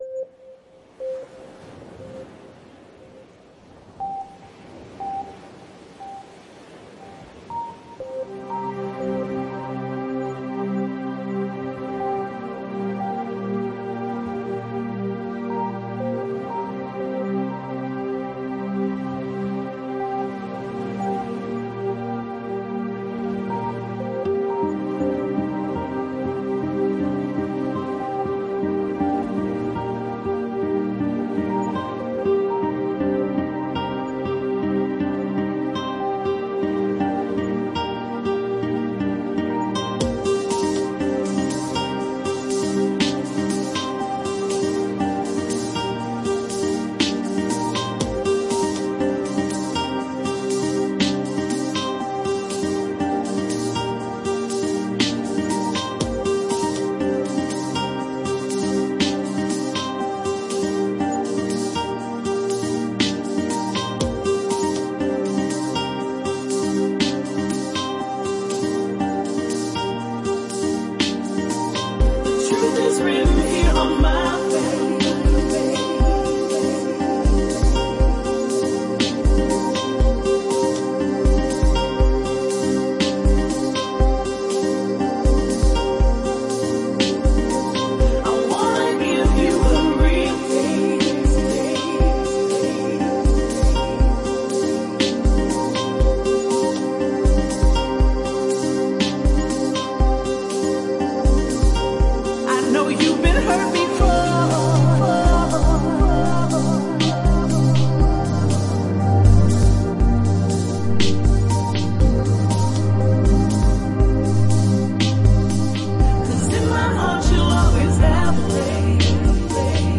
Chill out